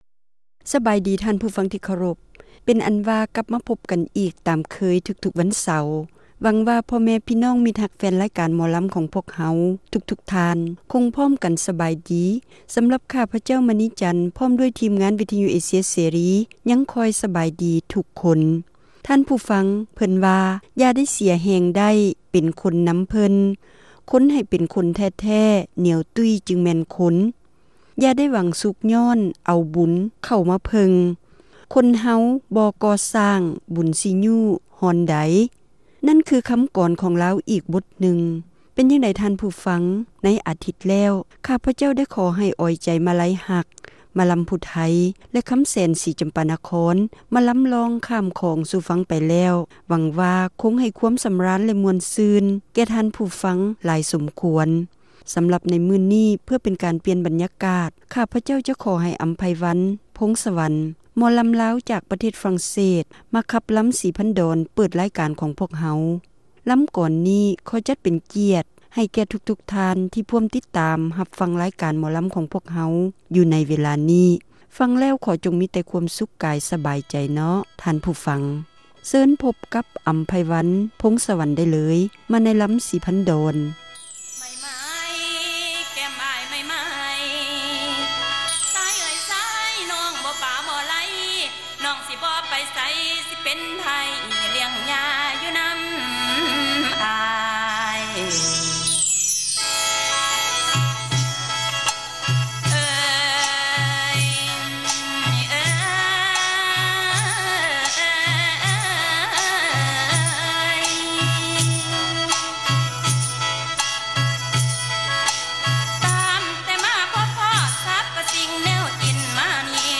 ຣາຍການໜໍລຳ ປະຈຳສັປະດາ ວັນທີ 30 ເດືອນ ພຶສຈິກາ ປີ 2007